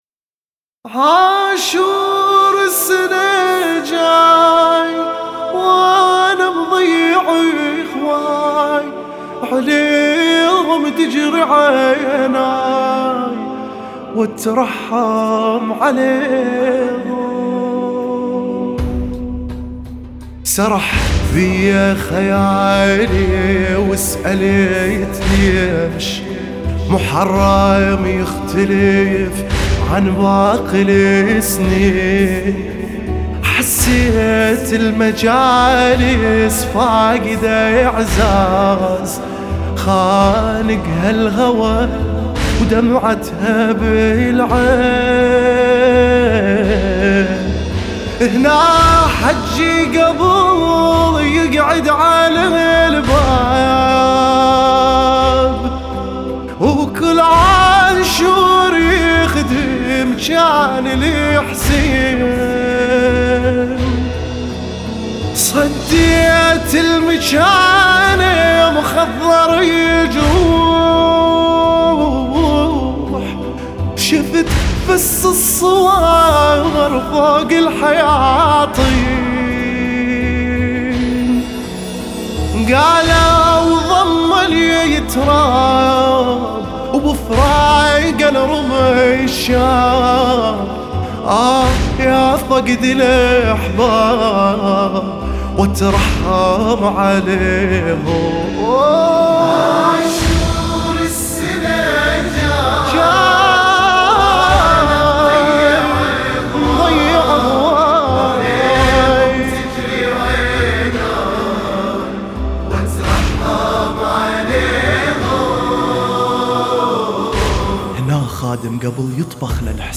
لطميات لطمية